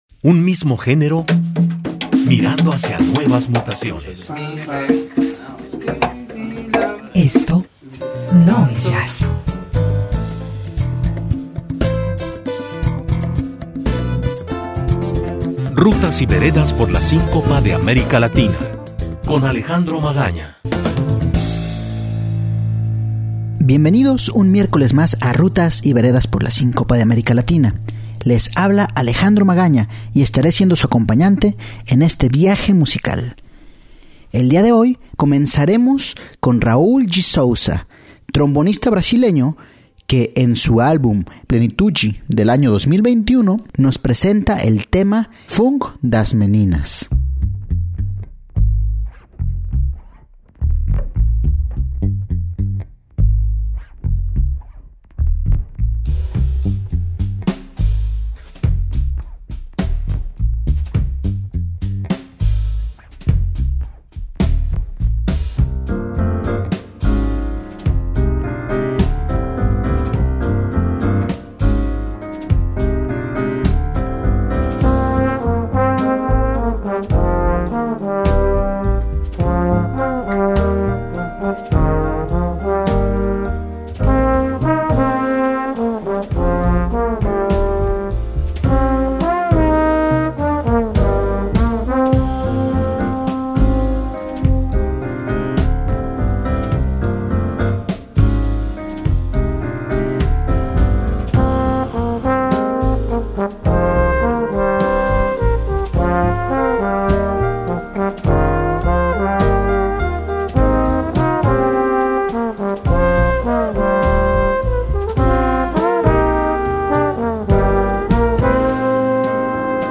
un recorrido por el jazz de América latina